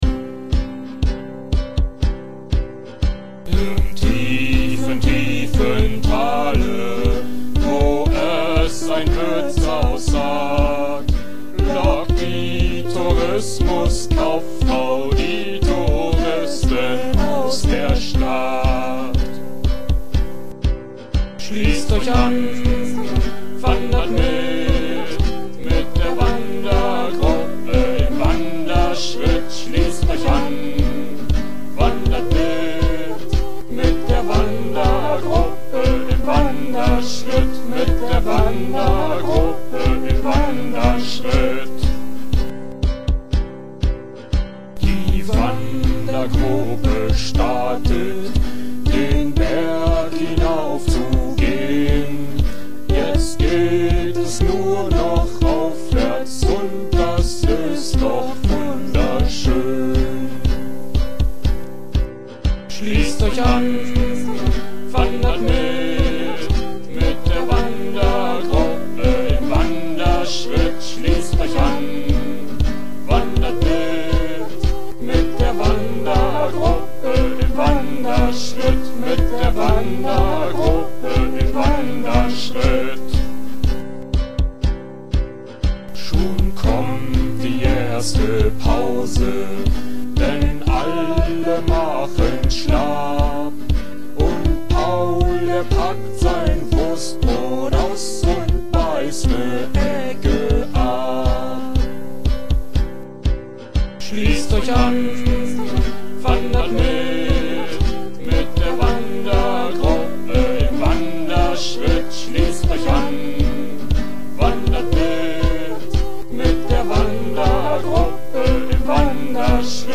Genre: Freie Musik - Elektro Rock